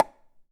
Road kill + sound effects